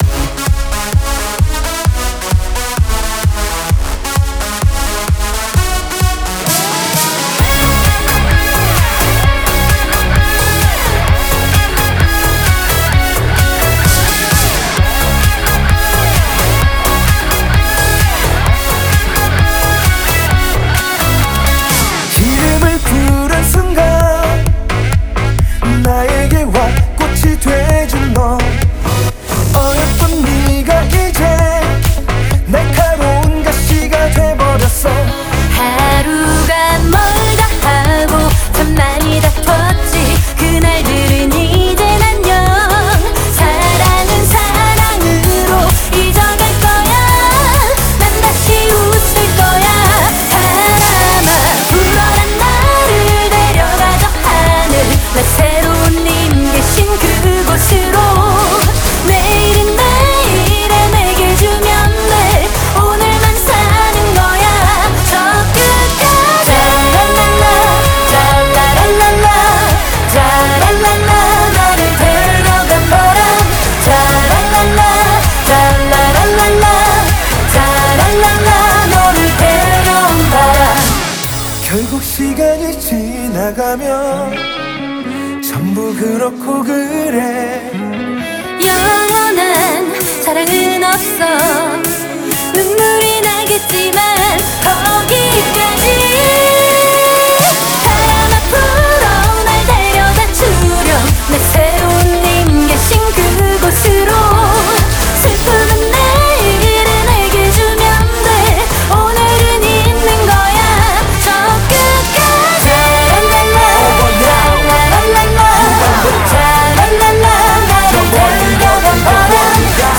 BPM130
Audio QualityPerfect (High Quality)
new K-POP release as July 2023